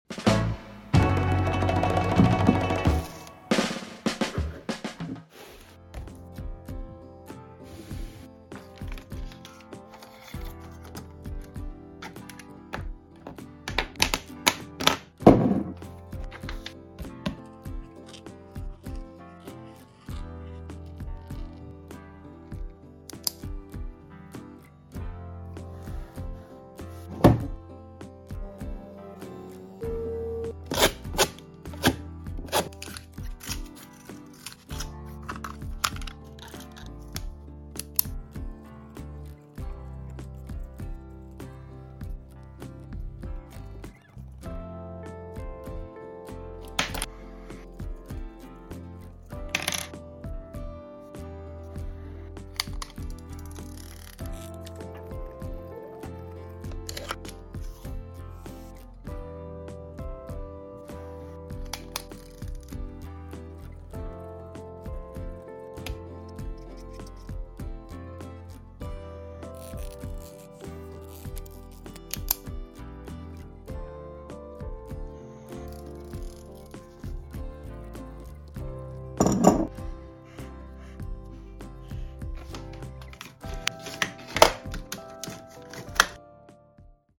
Note Taking Asmr 🌿📄 Sound Effects Free Download